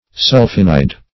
Sulphinide \Sul"phi*nide\, n. [Sulpho- + amine + anhydride.]